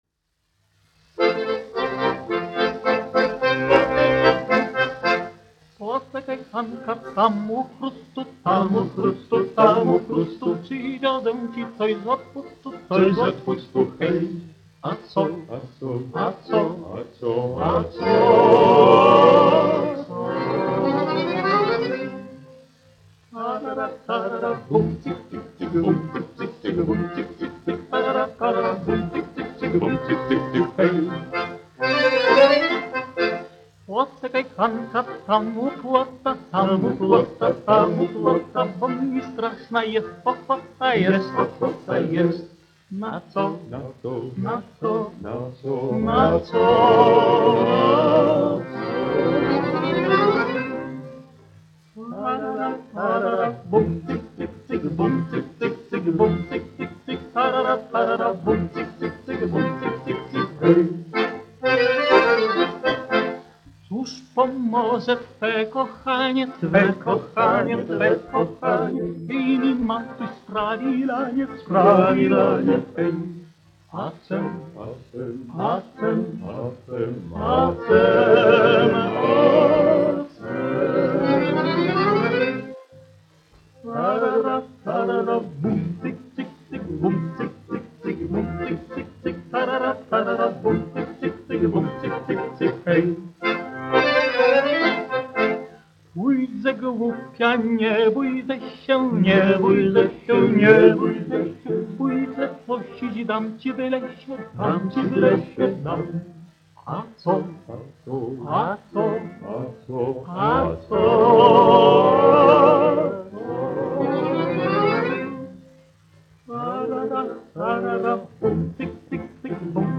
Подожди, Ханка : шуточная польская народная песня
1 skpl. : analogs, 78 apgr/min, mono ; 25 cm
Poļu tautasdziesmas
Vokālie kvarteti
Latvijas vēsturiskie šellaka skaņuplašu ieraksti (Kolekcija)